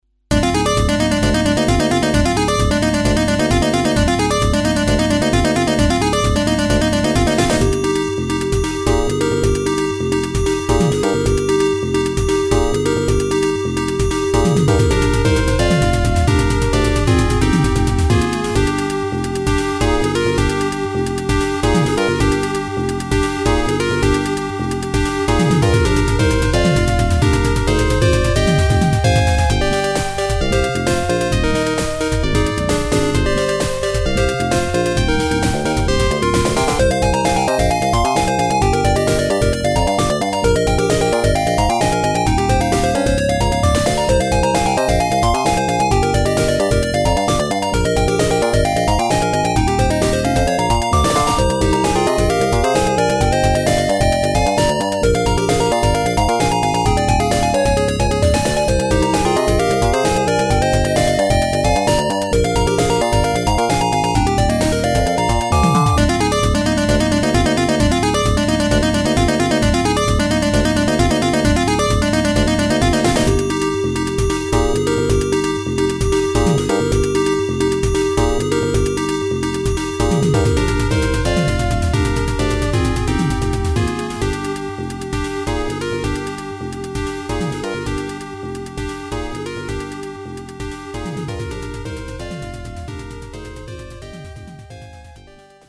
「ＦＭ音源」
というのは嘘で、ＰＣ−９８２１からサルベージしてきたＦＭ音源＋ＳＳＧ音源の曲。